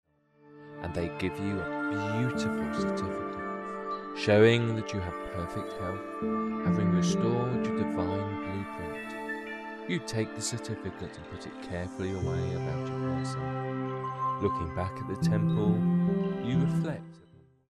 Body Healing Vocal Hypnosis MP3